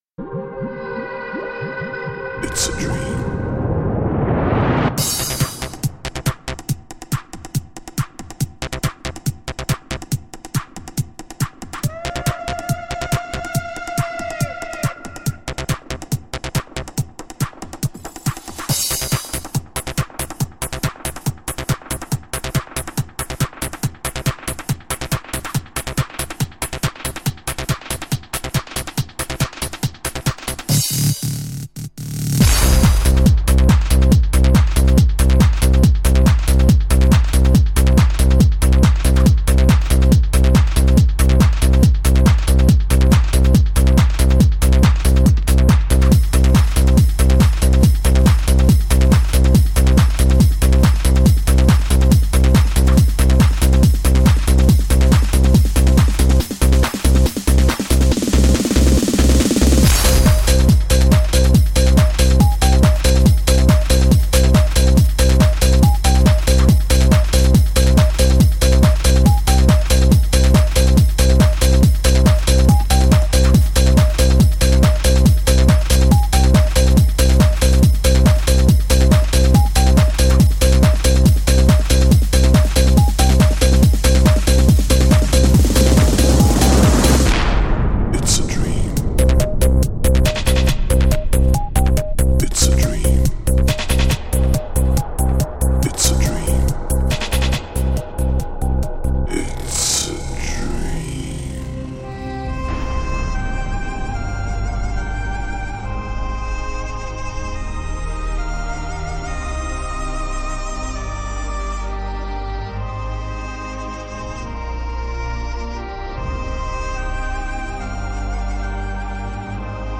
Жанр: Dream, Trance